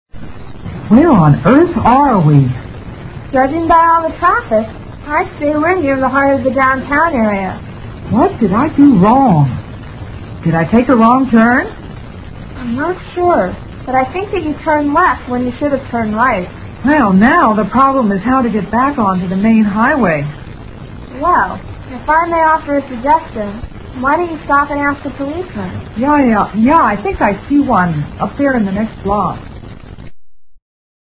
英语对话听力mp3下载Listen 8:WHERE ARE WE?
Dialogue 8